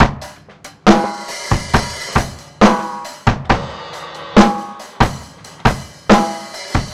C BEAT 1  -L.wav